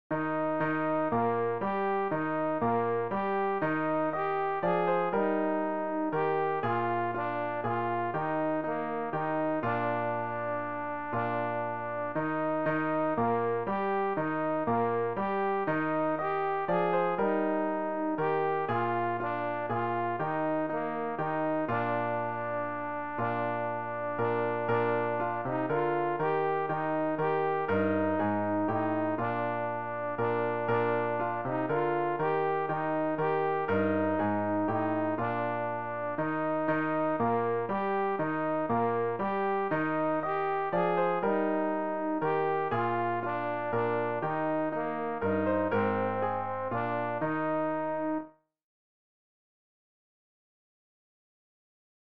rg-416-der-heiland-ist-geboren-alt.mp3